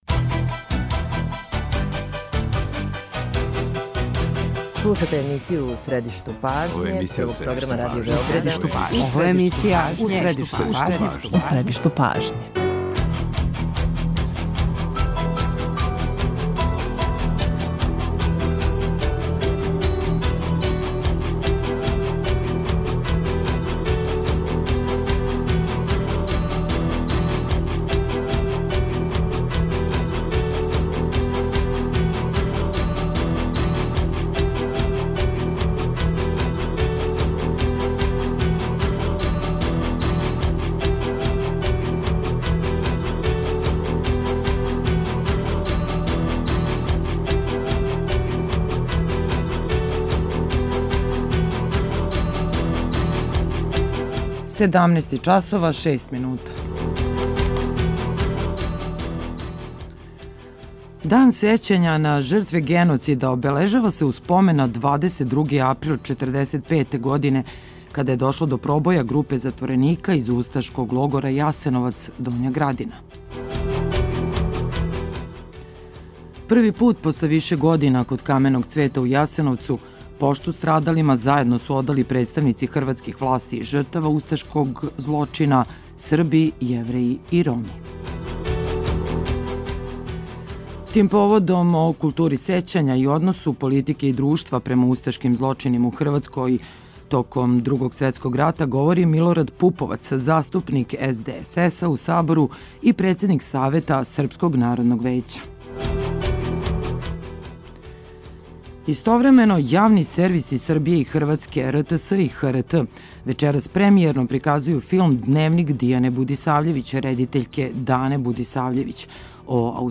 О култури сећања и односу политике и друштва према највећем злочину у Хрватској током Другог светског рата говори Милорад Пуповац, заступник СДСС-а у Сабору и председник Савјета Српског народног вијећа.
преузми : 38.14 MB У средишту пажње Autor: Редакција магазинског програма Свакога радног дана емисија "У средишту пажње" доноси интервју са нашим најбољим аналитичарима и коментаторима, политичарима и експертима, друштвеним иноваторима и другим познатим личностима, или личностима које ће убрзо постати познате.